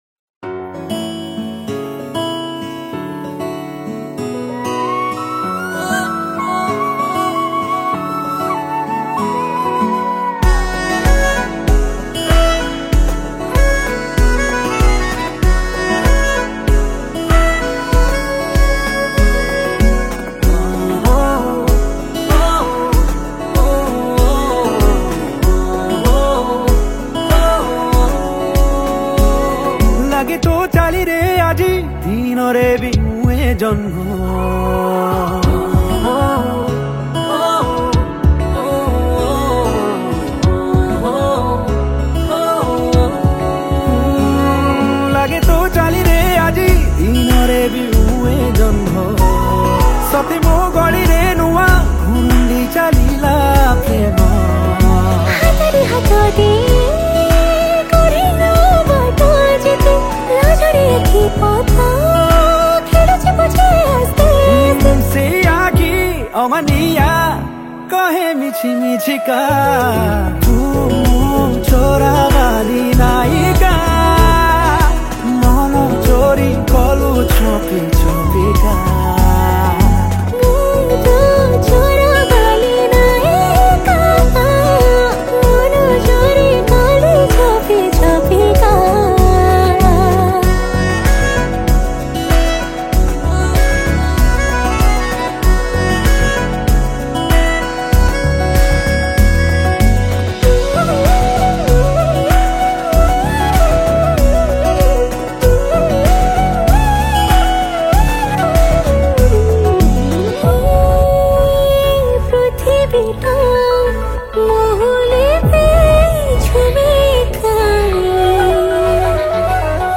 Winds